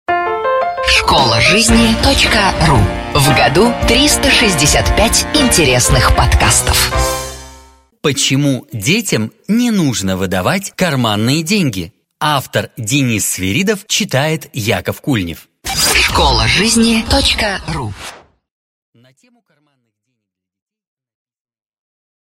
Аудиокнига Почему детям не нужно выдавать карманные деньги?